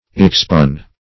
Meaning of expugn. expugn synonyms, pronunciation, spelling and more from Free Dictionary.
Search Result for " expugn" : The Collaborative International Dictionary of English v.0.48: Expugn \Ex*pugn"\ ([e^]ks*p[=u]n"), v. t. [L. expugnare; ex out + pugnare to fight, pugna fight.